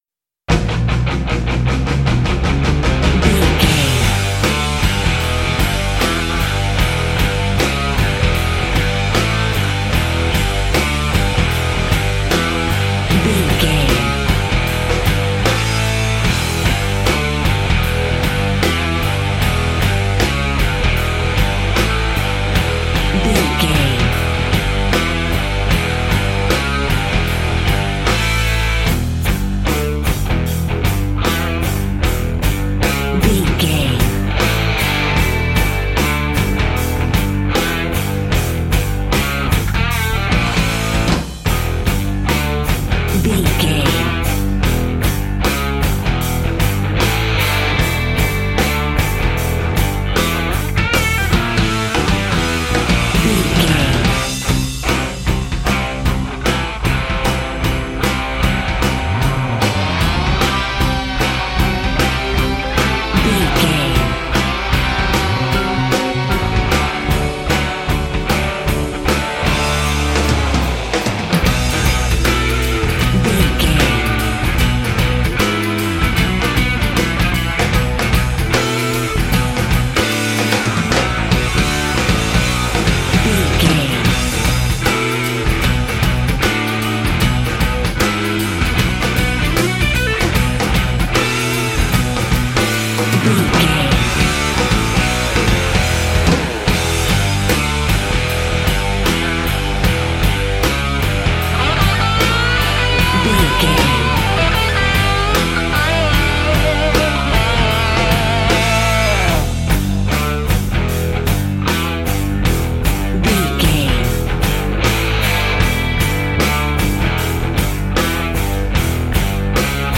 Aeolian/Minor
drums
electric guitar
bass guitar
Sports Rock
hard rock
lead guitar
aggressive
energetic
intense
powerful
nu metal
alternative metal